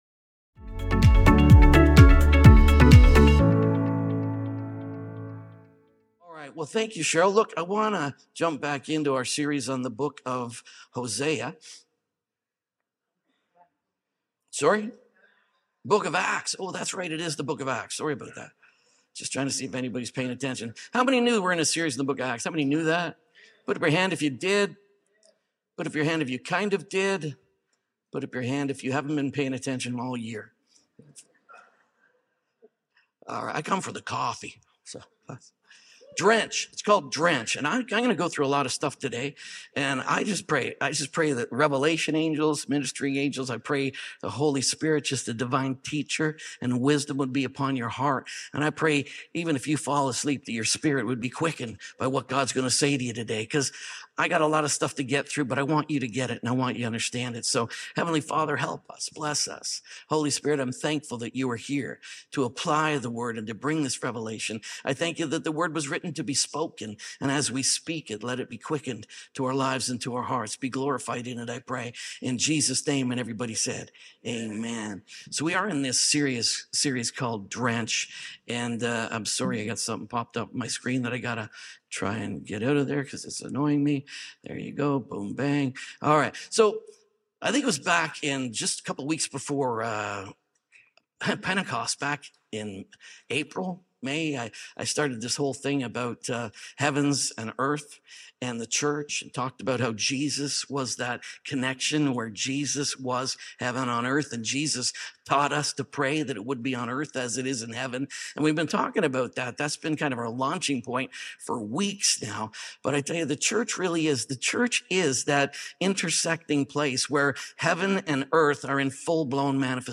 Two Faces Of Glory | Drench Series | SERMON ONLY.mp3